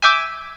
PIANO.wav